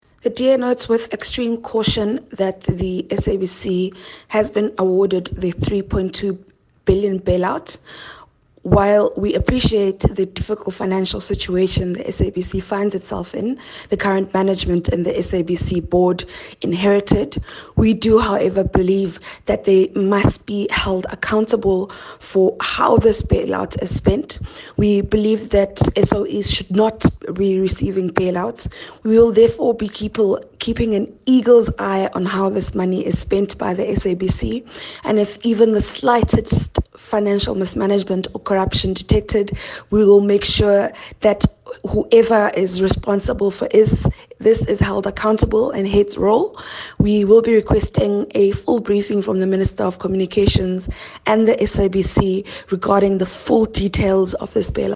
soundbite from Phumzile Van Damme.